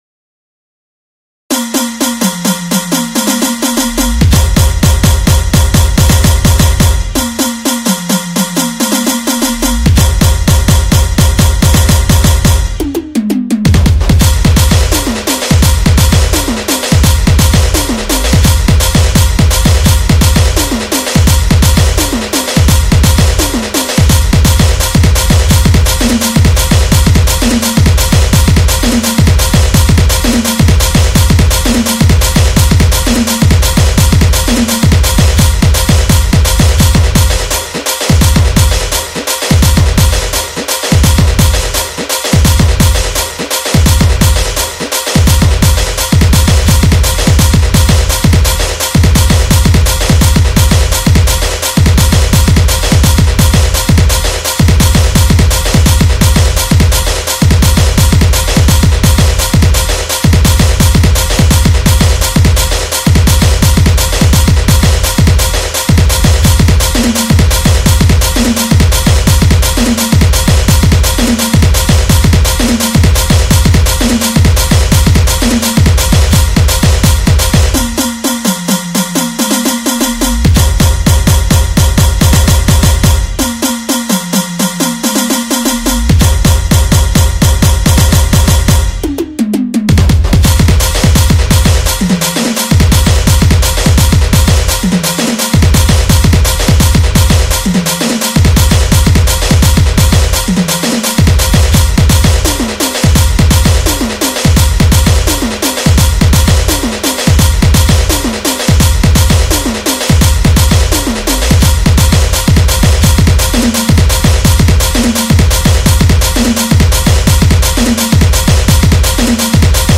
Instrumental Music And Rhythm Track